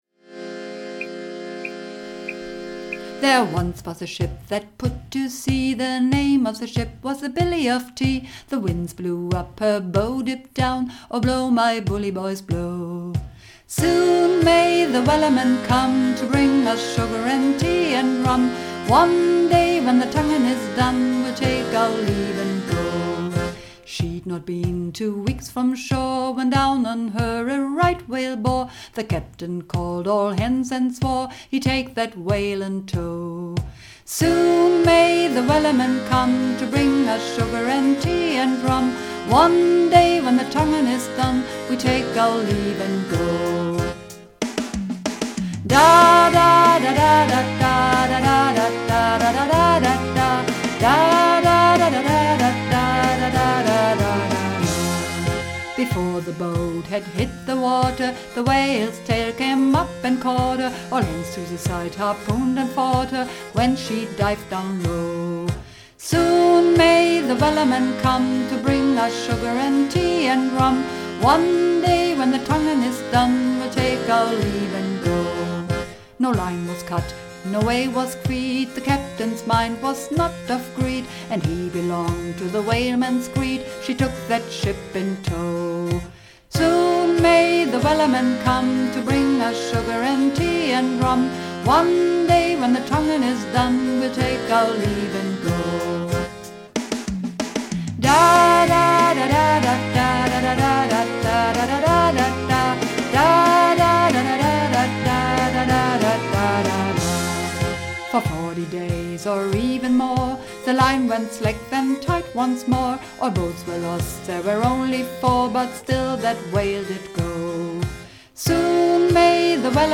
Übungsaufnahmen - Wellerman
Wellerman (Hauptstimme)
Wellerman__1_Hauptstimme.mp3